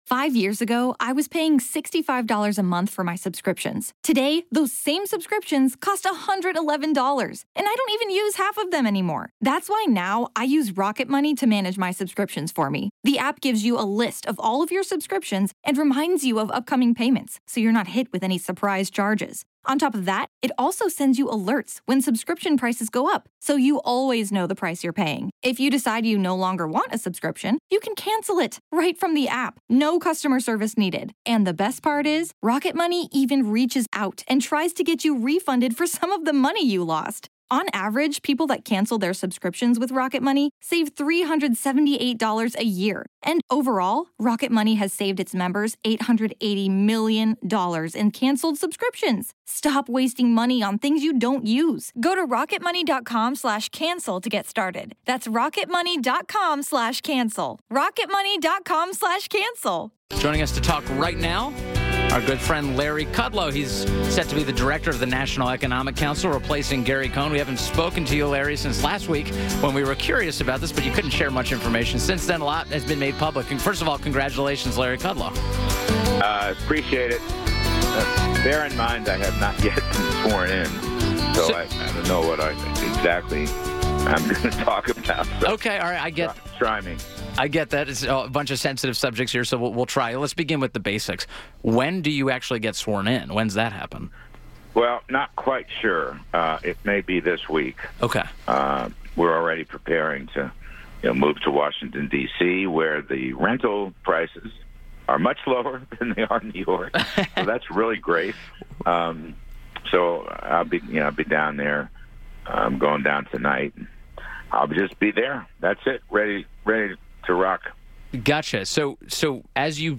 WMAL Interview - LARRY KUDLOW - 03.20.18